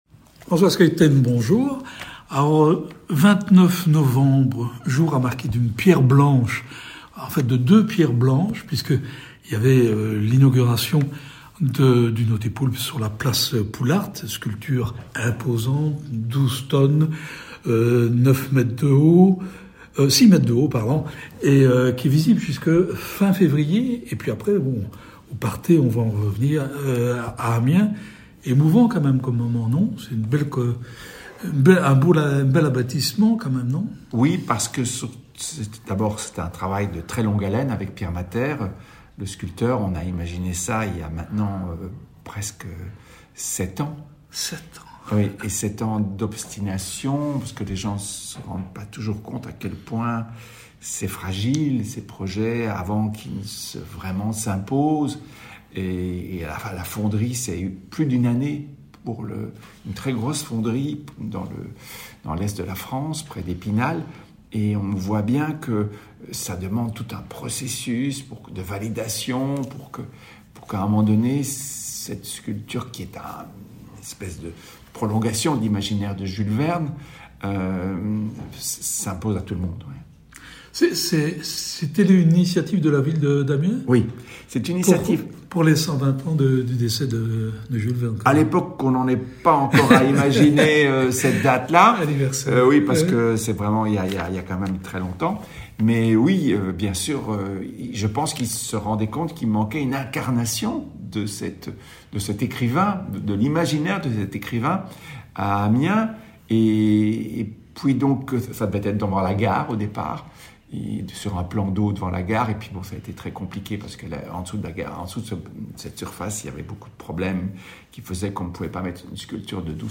« COMPULSION », un roman graphique passionnant créé par l’infatigable François Schuiten pour les dessins sur des textes du britannique Adam Roberts. Rencontre avec le dessinateur.